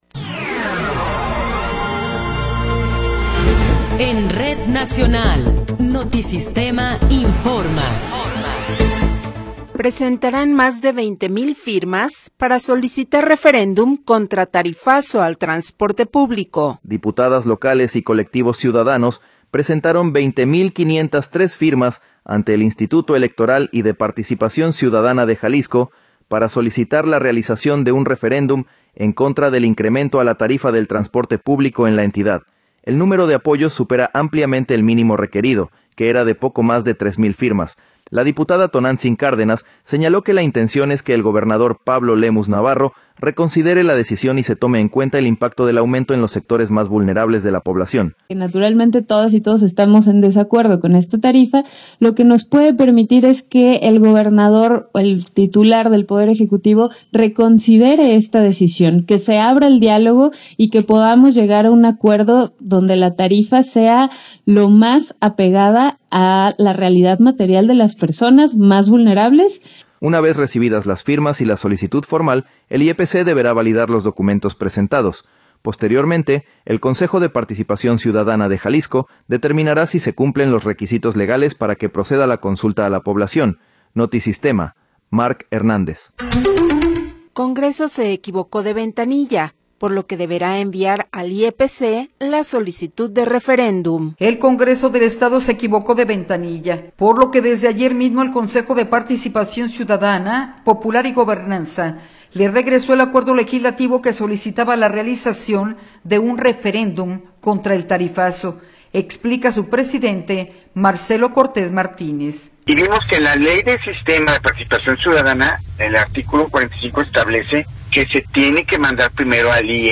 Noticiero 15 hrs. – 23 de Enero de 2026